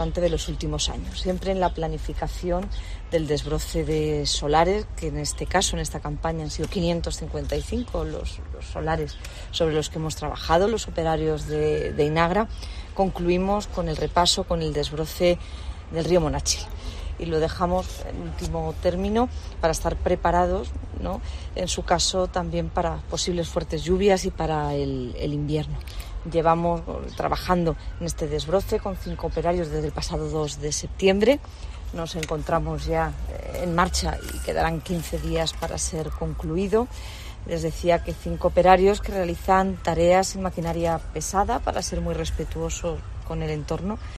Marifrán Carazo, alcaldesa de Granada